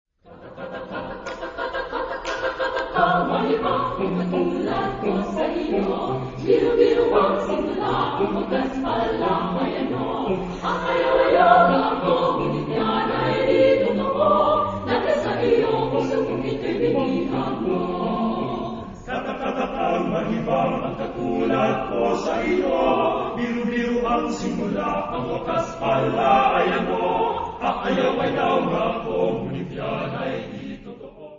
Text in: Filipino ; onomatopoeia
Genre-Style-Form: Secular ; Traditional ; Popular ; Choir
Mood of the piece: rhythmic
Type of Choir: SSAATTBB  (8 mixed voices )
Tonality: modal